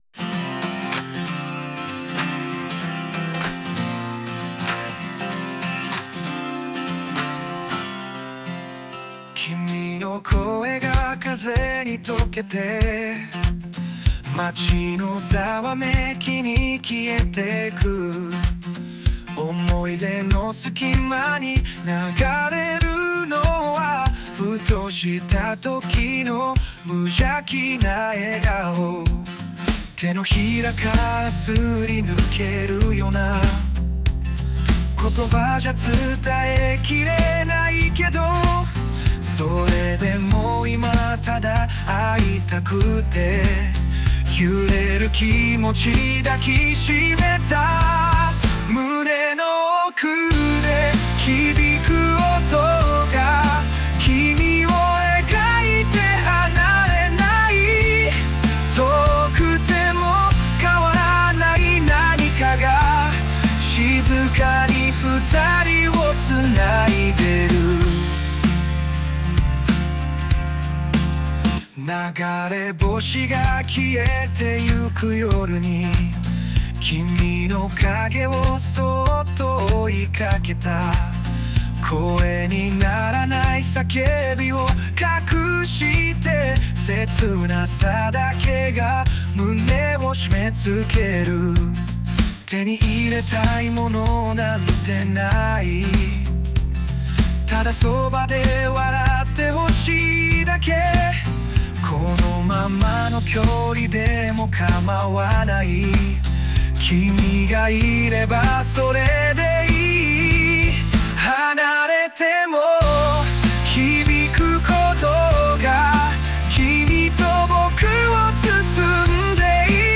男性ボーカル邦楽 男性ボーカル
著作権フリーオリジナルBGMです。
男性ボーカル（邦楽・日本語）曲です。
またまた少しエモい系、切ない系のラブソングになりました。